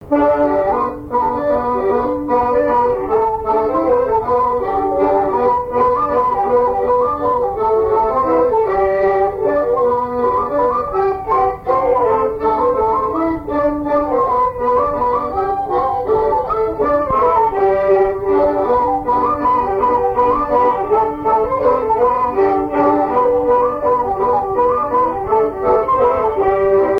Localisation Rochetrejoux
Enfantines - rondes et jeux
danse : mazurka
Pièce musicale inédite